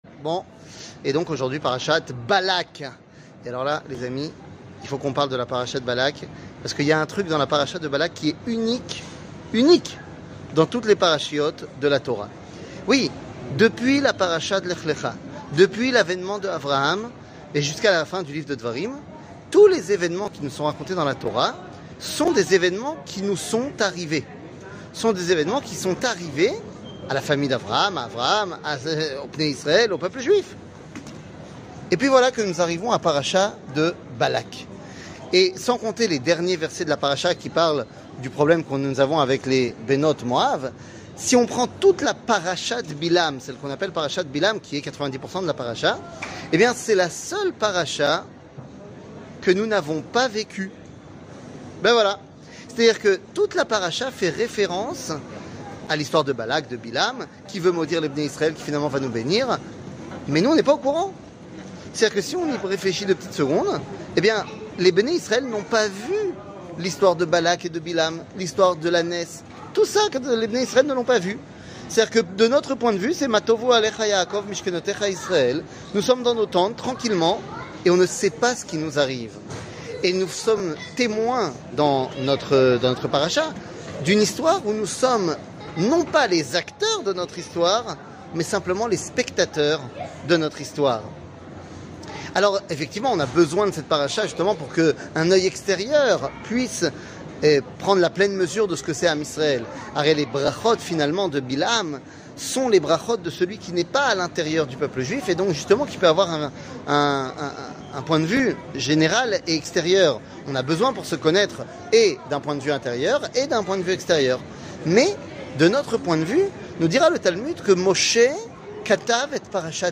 Le point Paracha, Balak, Acteur ou spectateur 00:04:36 Le point Paracha, Balak, Acteur ou spectateur שיעור מ 29 יוני 2023 04MIN הורדה בקובץ אודיו MP3 (4.21 Mo) הורדה בקובץ וידאו MP4 (8.35 Mo) TAGS : שיעורים קצרים